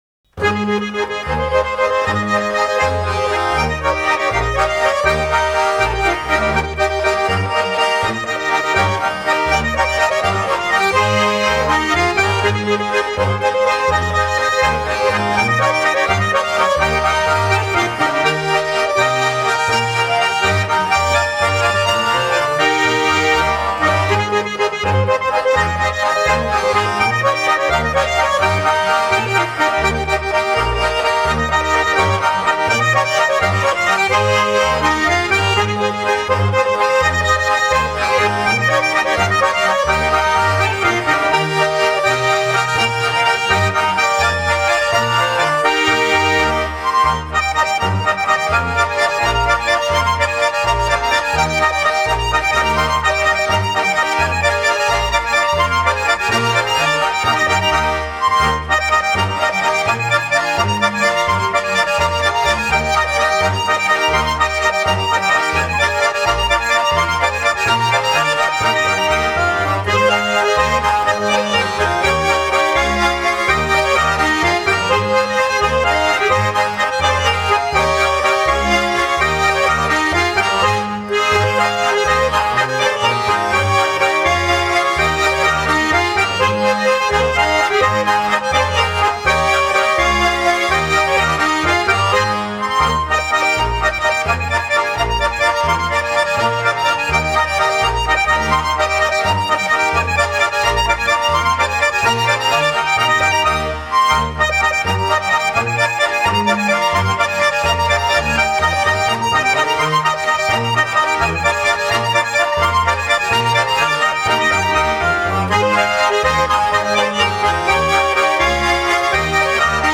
Live Aufnahmen
Ländler